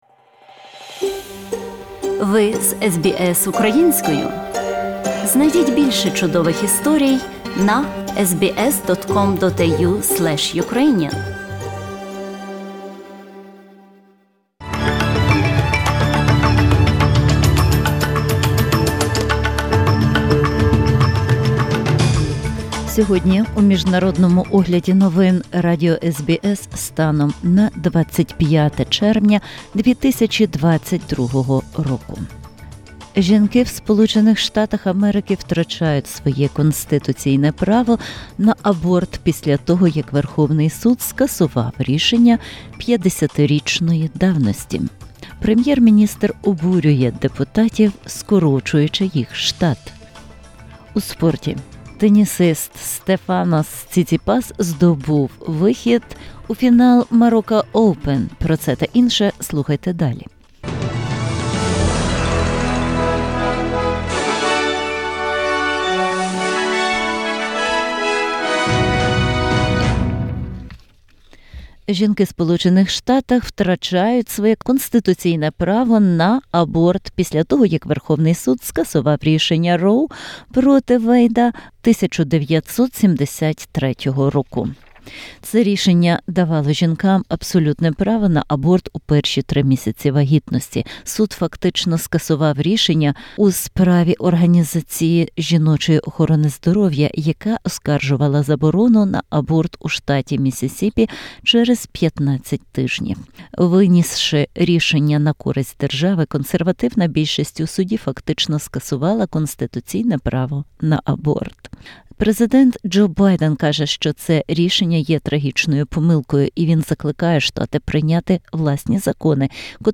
SBS News in Ukrainian - 25/06/2022